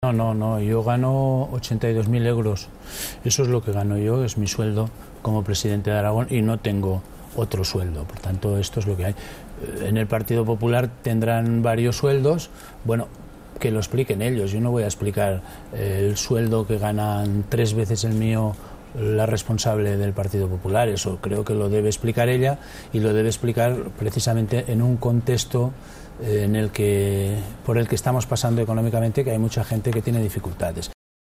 Iglesias, que ha realizado estas declaraciones esta mañana durante una entrevista en “Los Desayunos de TVE”, ha indicado que él como presidente de Aragón percibe un total de 82.000 euros al año “y no tengo otro sueldo”.
Cortes de audio de la rueda de prensa